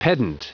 Prononciation du mot pedant en anglais (fichier audio)
Prononciation du mot : pedant